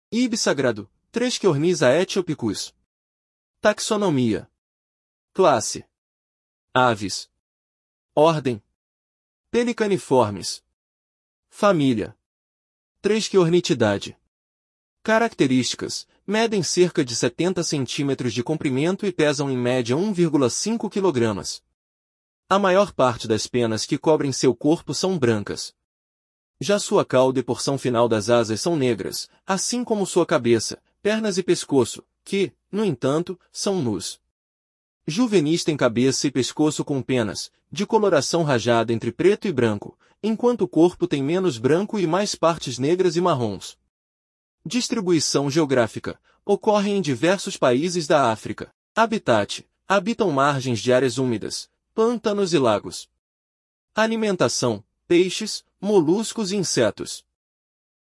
Íbis-sagrado (Threskiornis aethiopicus)